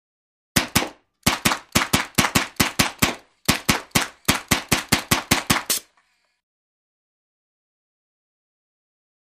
Paint ball Guns; Multiple Fires Until Empty With Air Release At End.